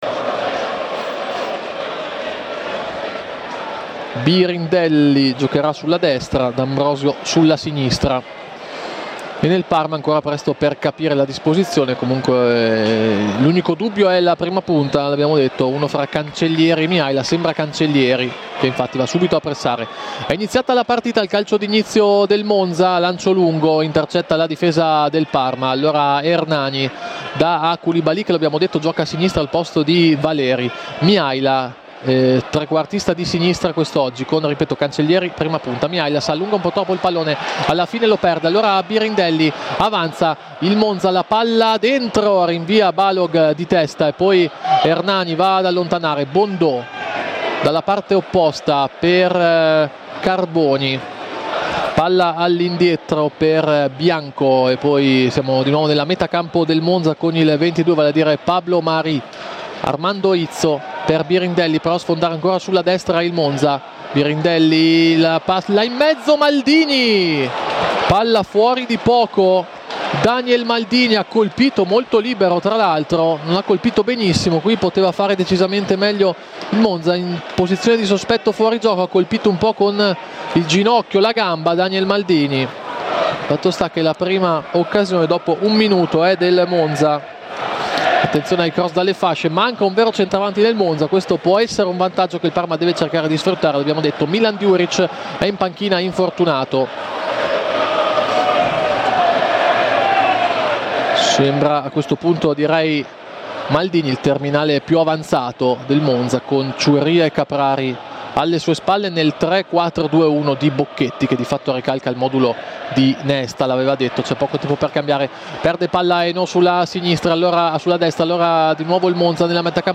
Radiocronache Parma Calcio Parma - Monza 1° tempo - 28 dicembre 2024 Dec 28 2024 | 00:47:19 1x 00:00 / 00:47:19 Subscribe Share RSS Feed Share Link Embed